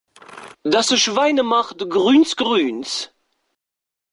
Soundboard
Das Schwein macht grünz.mp3